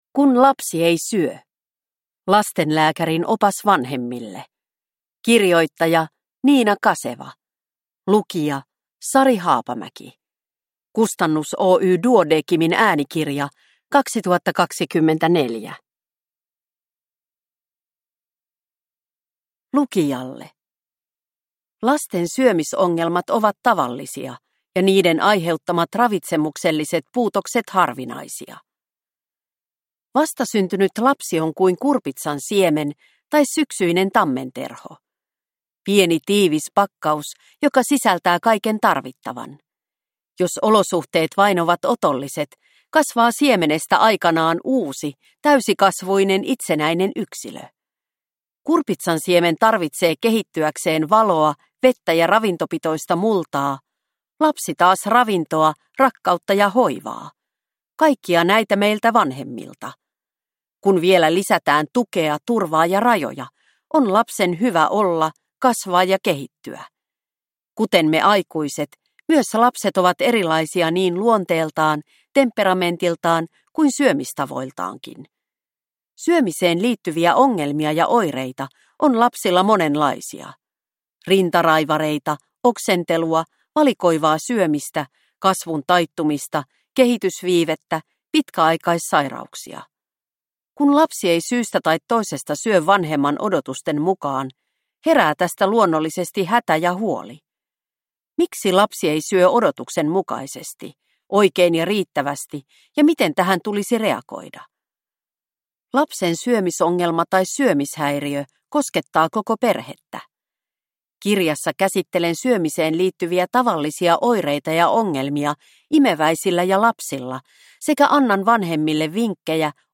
Kun lapsi ei syö – Ljudbok